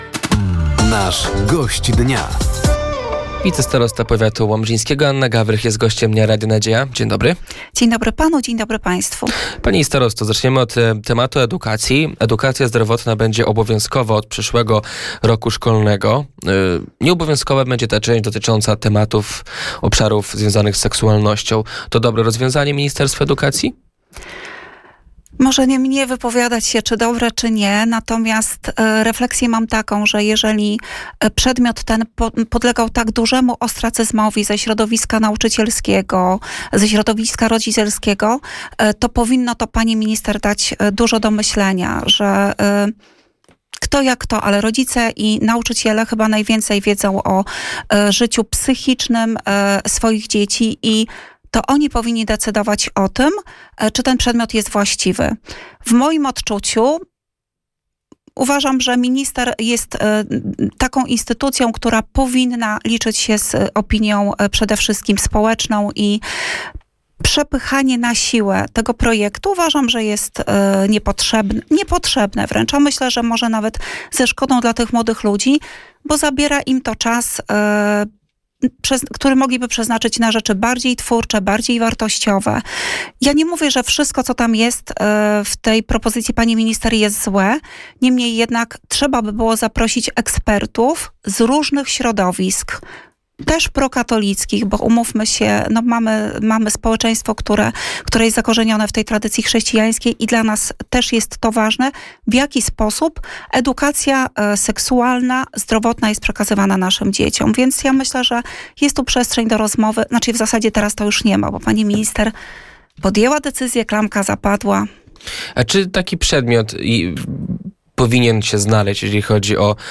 Gościem Dnia Radia Nadzieja była wicestarosta powiatu łomżyńskiego Anna Gawrych. Tematem rozmowy były między innymi inwestycje drogowe, działania związane z programem Ochrony Ludności i Obrony Cywilnej, sezon kulturalny i prace w Muzeum w Drozdowie.